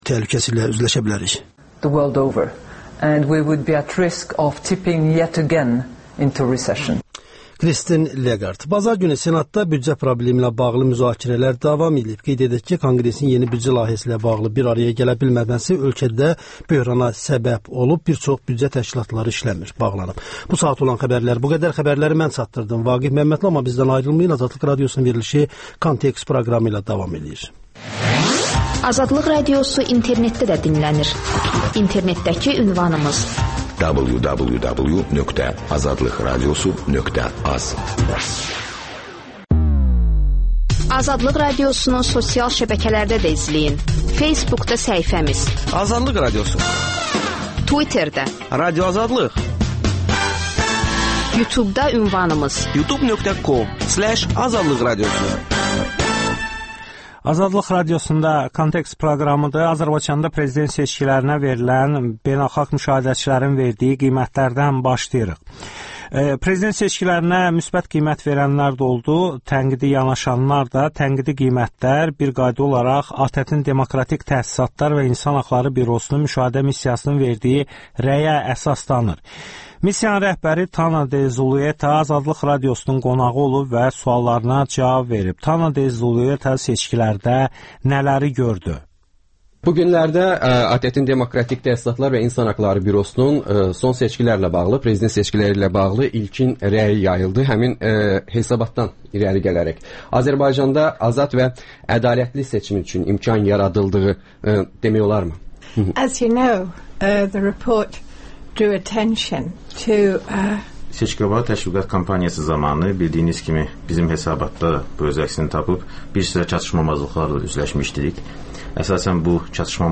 ATƏT/DTİHB-nin seçki müşahidə missiyasının başçısı xanım Tana de Zulueta AzadlıqRadiosuna müsahibə verib.